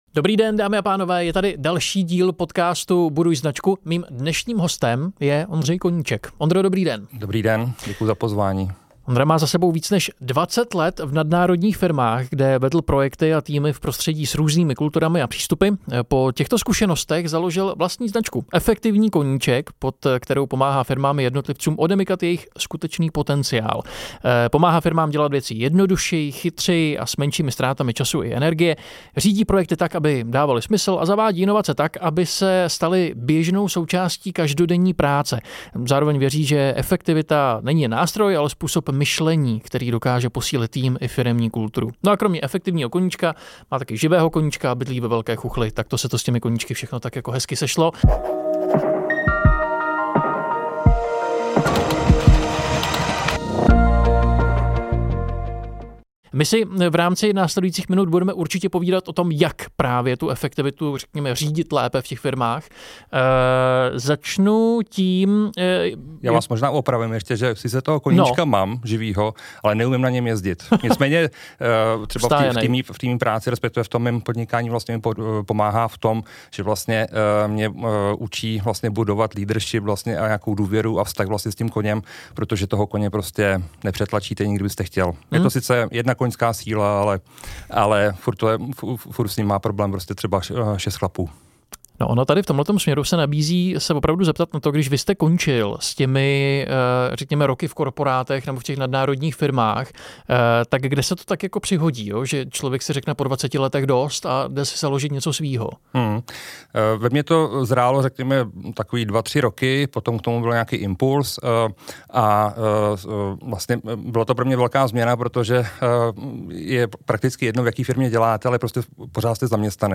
V rozhovoru najdete to, co vám pomůže už zítra: – Jak najít čas, kterého máme všichni stejně – Jak nemít kalendář plný zbytečných meetingů – Jak se s projektem nedostat do slepé uličky